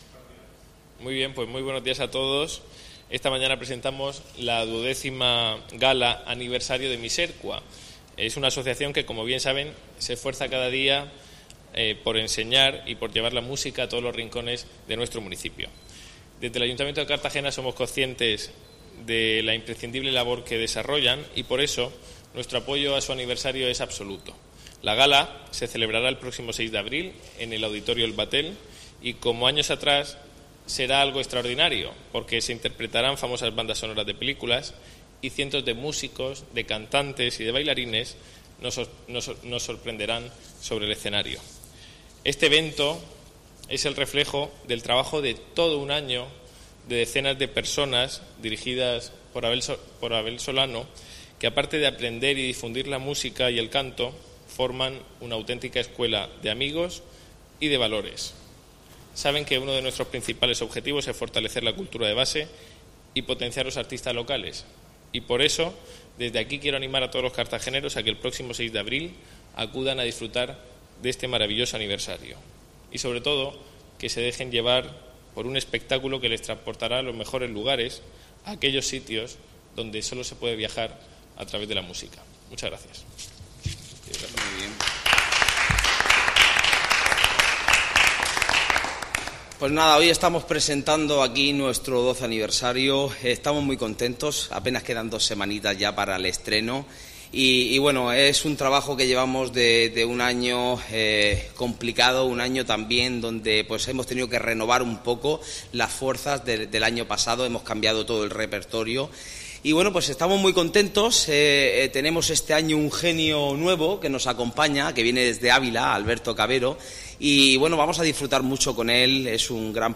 Enlace a Presentación de la Gala Misercua